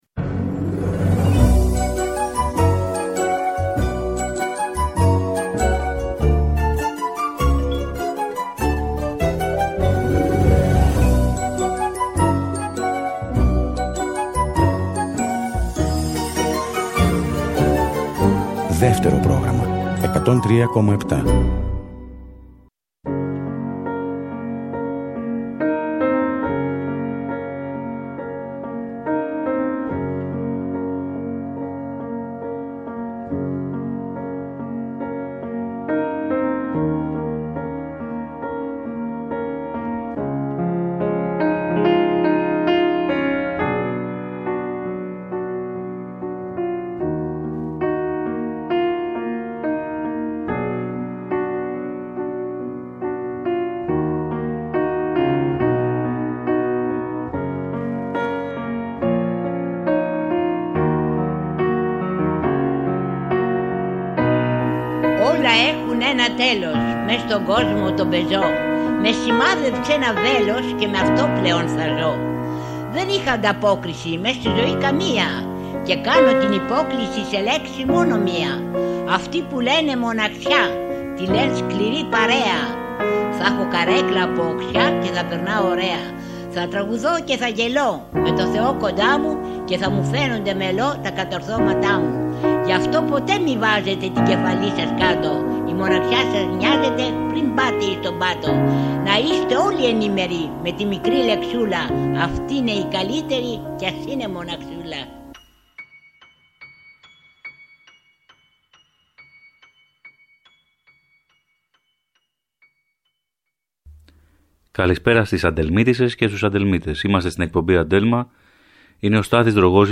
Τραγούδια θηλυκά , εύθραυστα και δυνατά όπως οι γυναίκες αυτό το Σάββατο 9 Μαρτίου 2024 στην Αντέλμα στις 5 το απόγευμα ακριβώς!